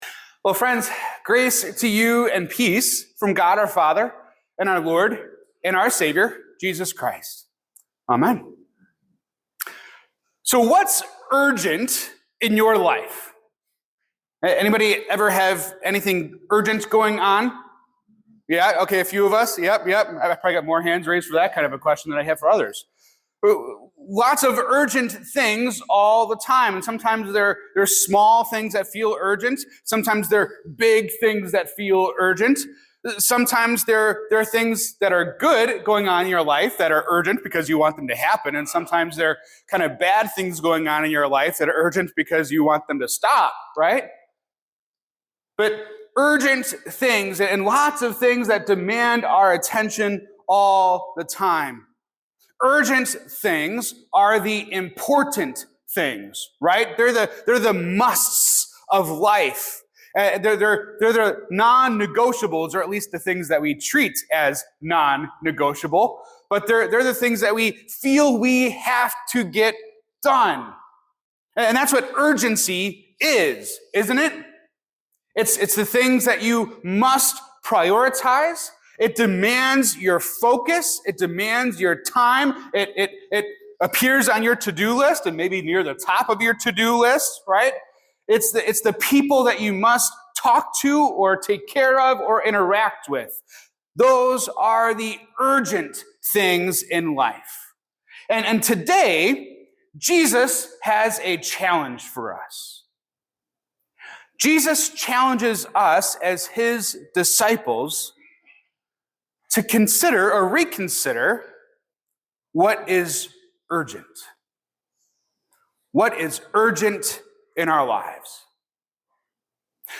This sermon underscores the profound urgency of the cross in a Christian's journey, drawing insights from Luke 9:51-62. Jesus's unwavering focus is His mission to Jerusalem, where His ultimate sacrifice would bring forgiveness, redemption, and the promise of resurrection. The message highlights Jesus's correction of His disciples, who initially failed to grasp the universal scope of His mission, extending even to the Samaritans.